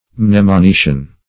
Search Result for " mnemonician" : The Collaborative International Dictionary of English v.0.48: Mnemonician \Mne`mo*ni"cian\, n. One who instructs in the art of improving or using the memory.